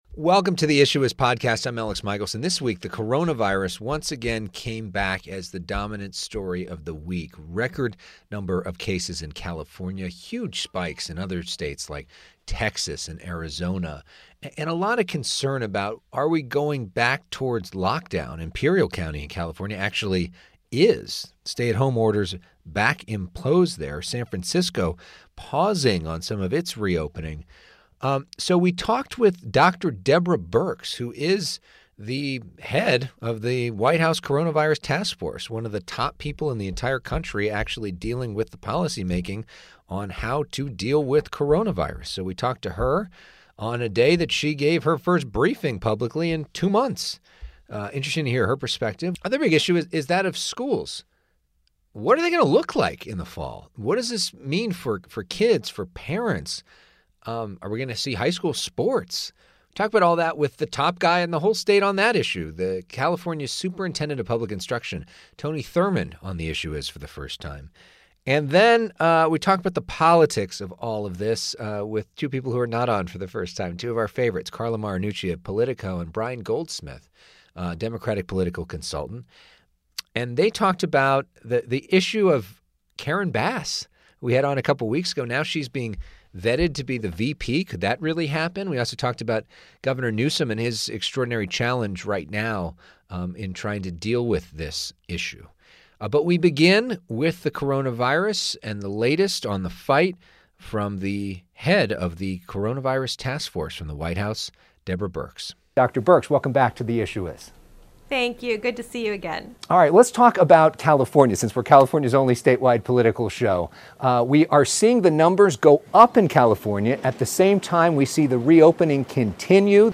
Dr. Deborah Birx speaks about the recent spike in coronavirus cases
broadcast from FOX 11 Studios in Los Angeles.